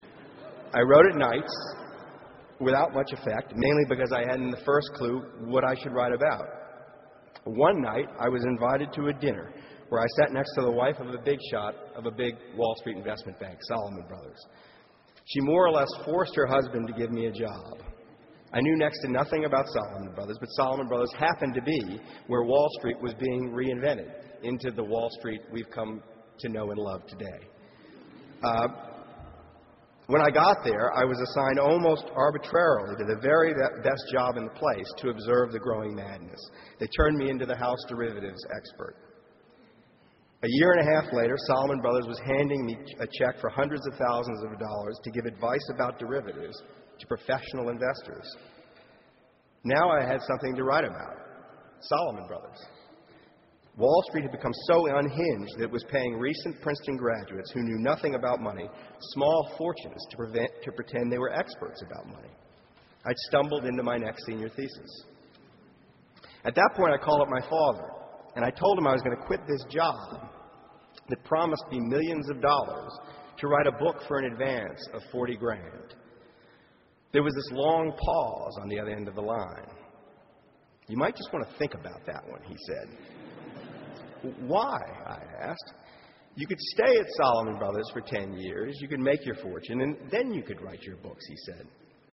公众人物毕业演讲 第139期:2012年Michael Lewis普林斯顿大学(3) 听力文件下载—在线英语听力室